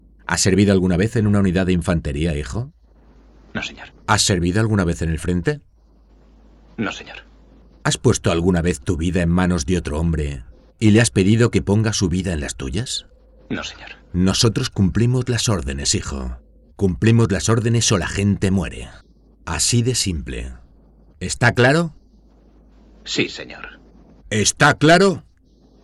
Filmtrailer
Voz neutral, emotiva, energética y divertida
Profesional Studio at home